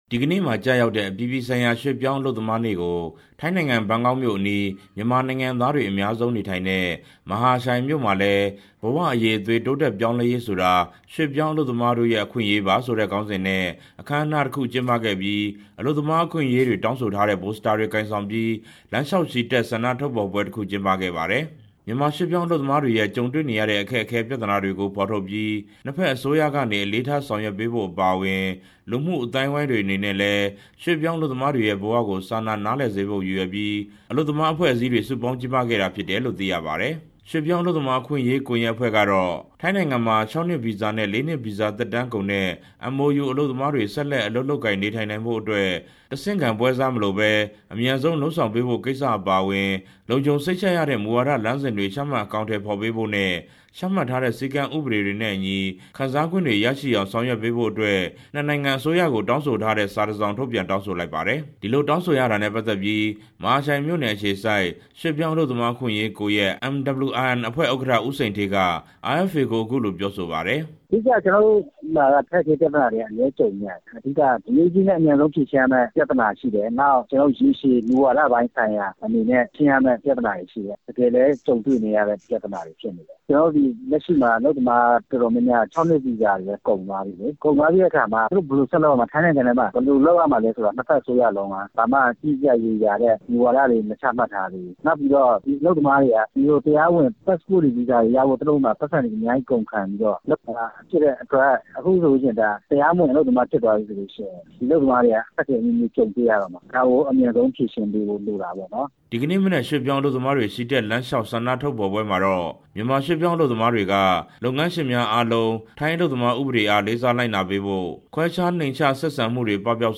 သတင်းပေးပို့ချက်